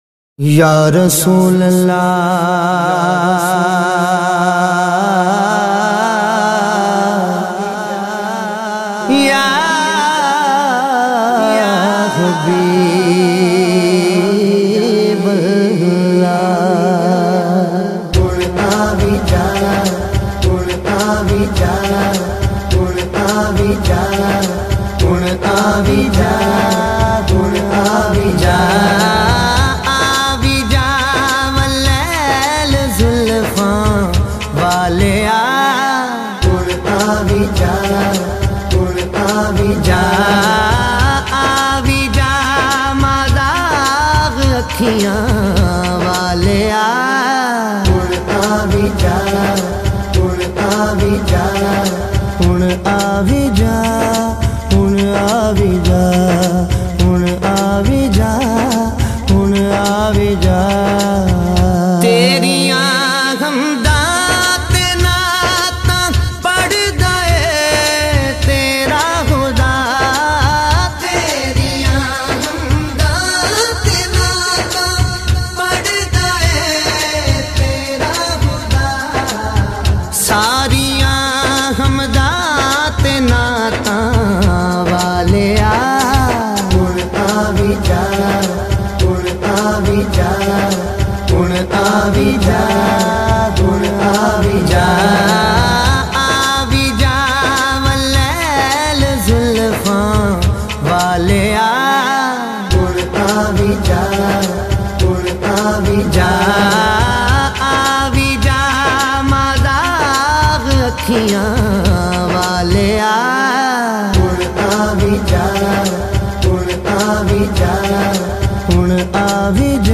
Explore and download a variety of Islamic MP3s.